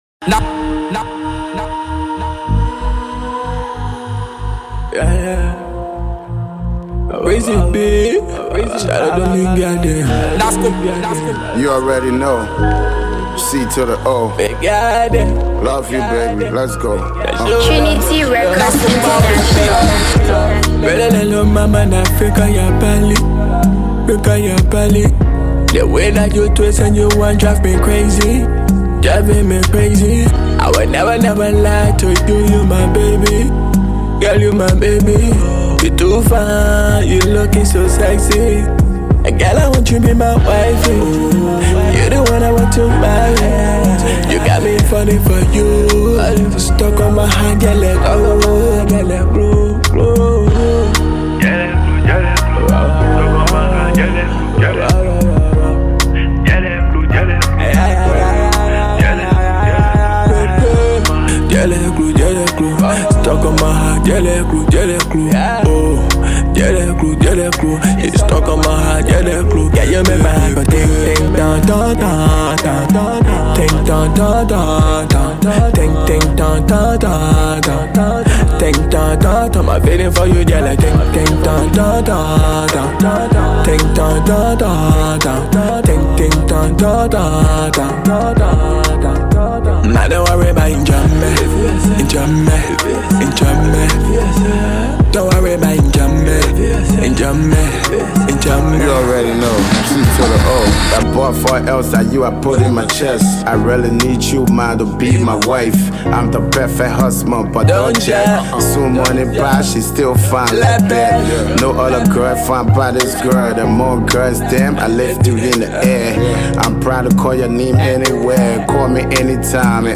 Afro-Pop
new love vibe
definitely a rap